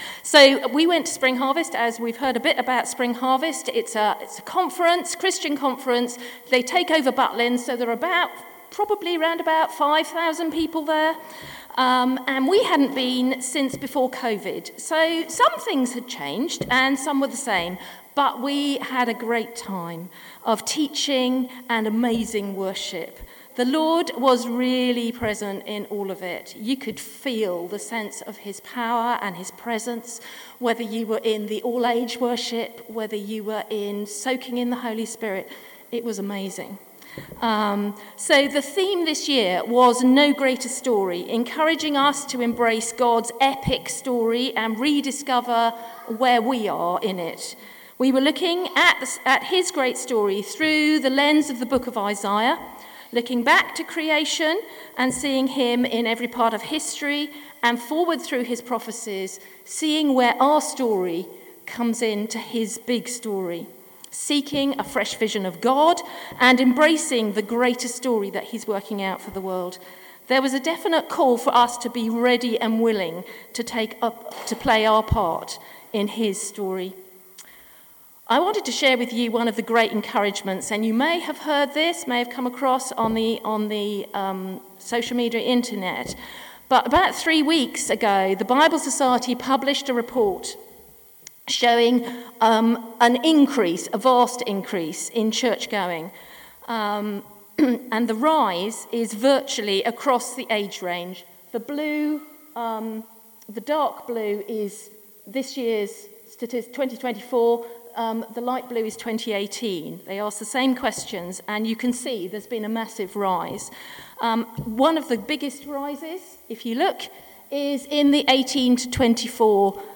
Talks, 27 April 2025